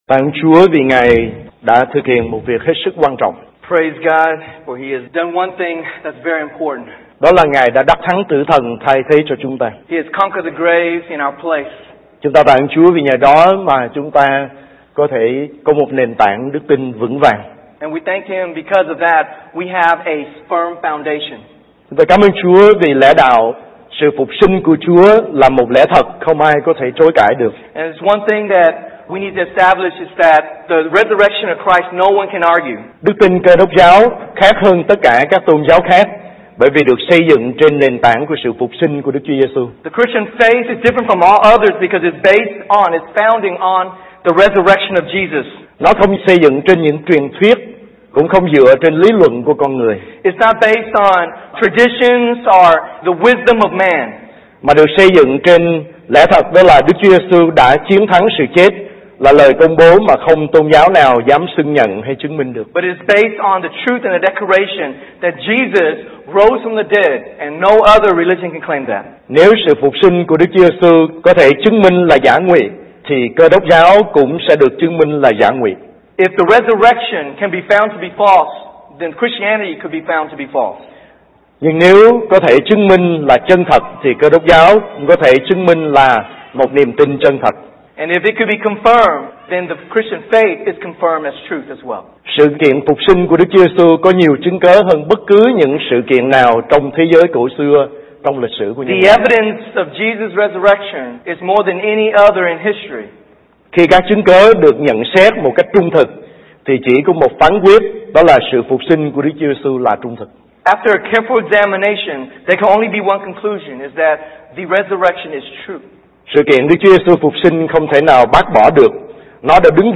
Giảng Luận
Lễ Phục Sinh